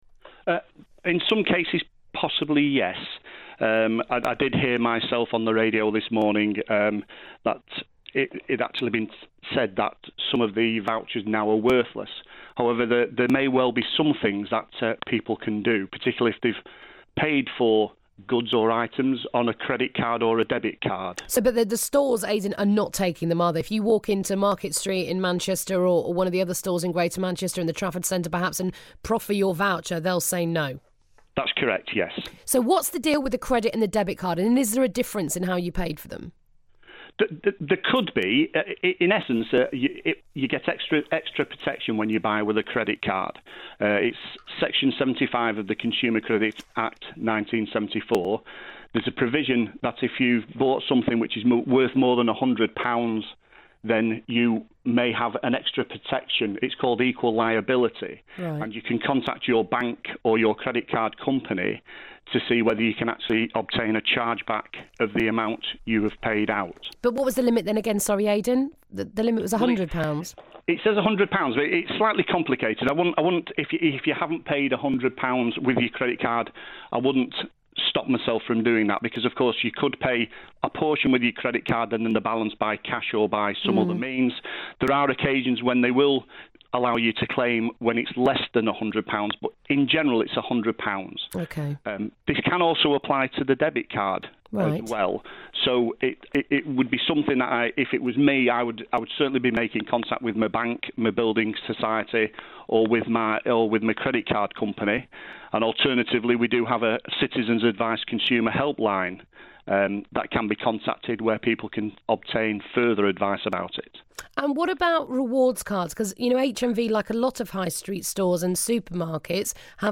BBC Radio Manchester / HMV gift vouchers - refund or no refund?